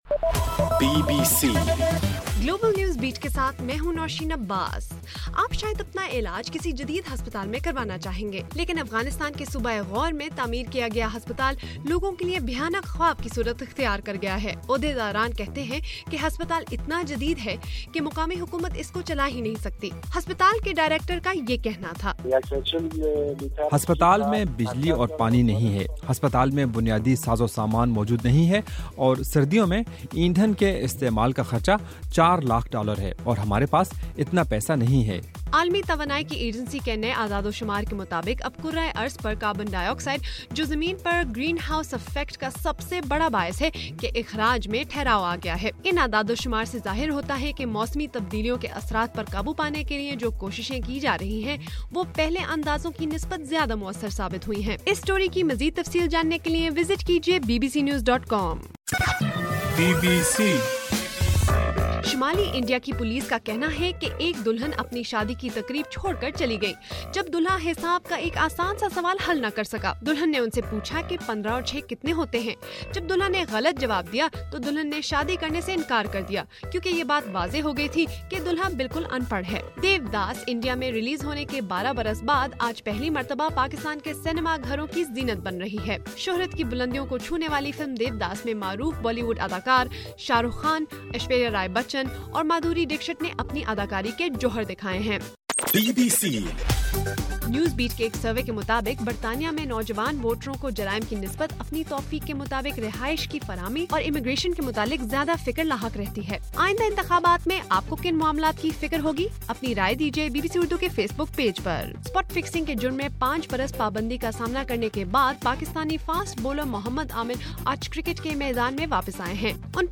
مارچ 13: رات 11 بجے کا گلوبل نیوز بیٹ بُلیٹن